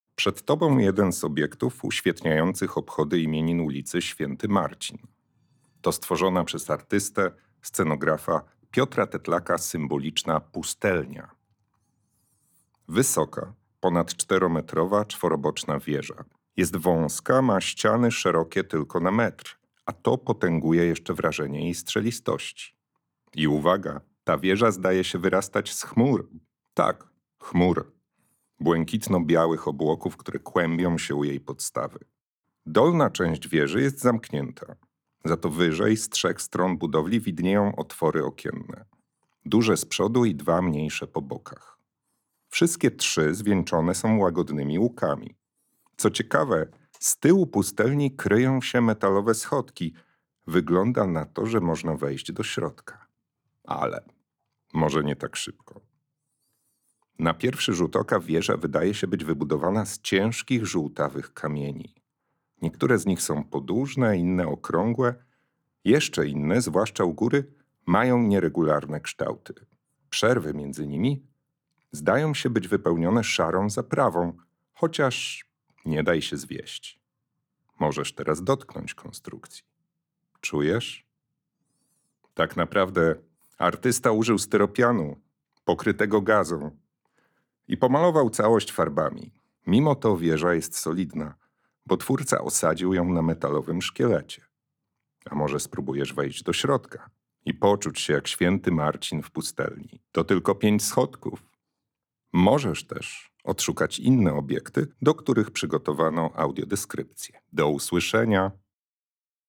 Pustelnia – audiodeskrypcja